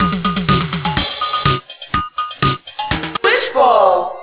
A short loop that repeats endlessly until you hit a key.